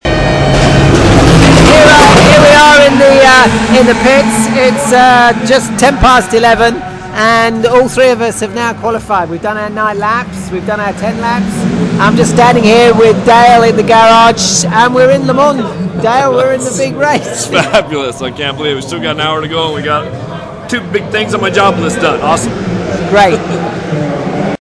In The Pit At Le Mans Having Qualified All 3 Drivers. We Are In The Race!